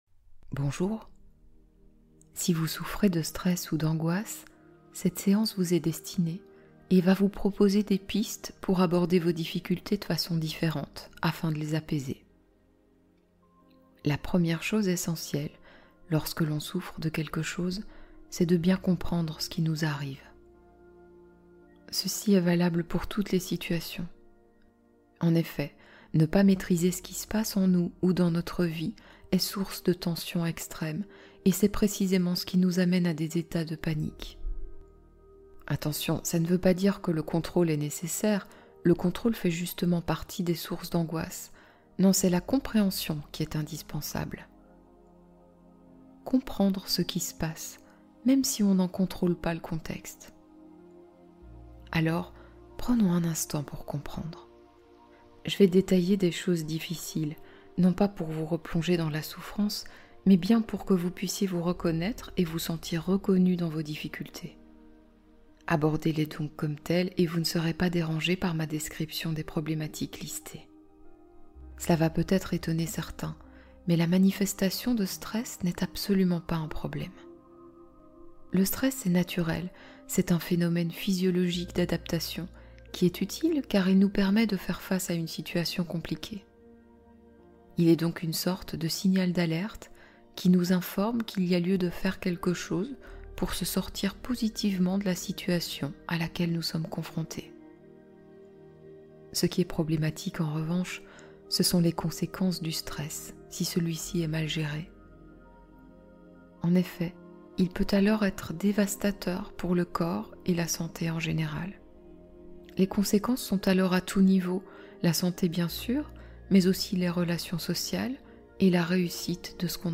Paix intérieure : 2h d’affirmations positives